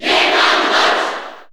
Category: Crowd cheers (SSBU) You cannot overwrite this file.
Mr._Game_&_Watch_Cheer_Japanese_SSB4_SSBU.ogg